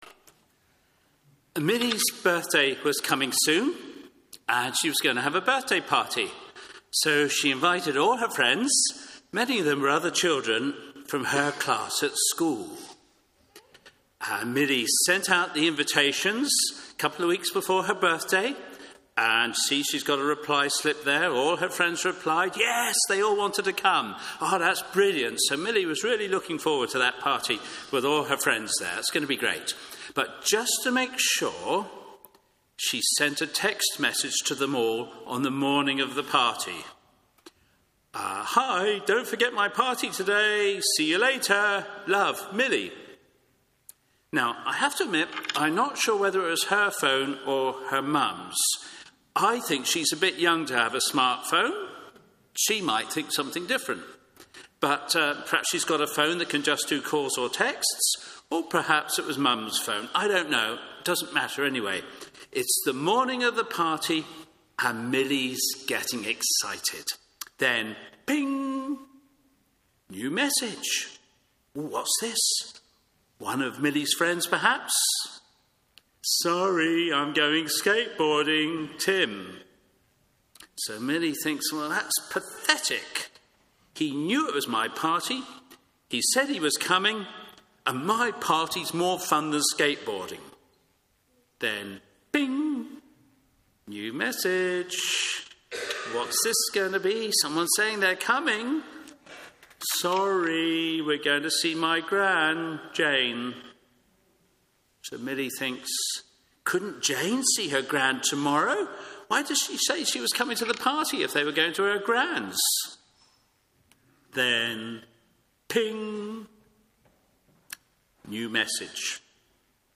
Media for Morning Service on Sun 02nd Jul 2023 10:30 Speaker
Theme: Sermon In the search box please enter the sermon you are looking for.